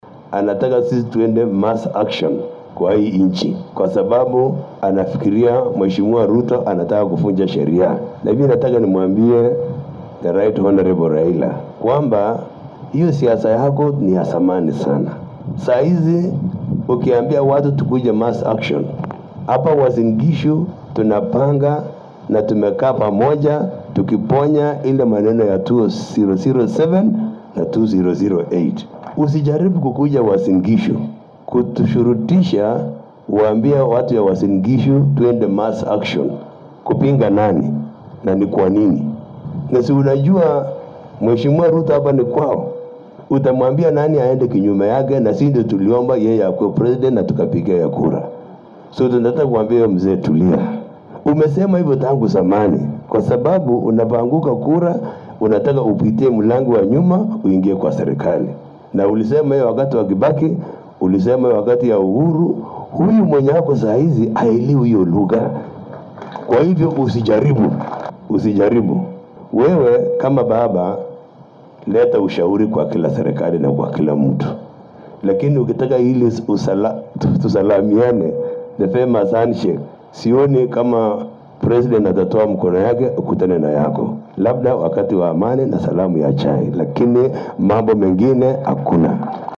Speaking today at AIC Nandi Gaa during a thanksgiving service